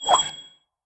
nut_fly_02.ogg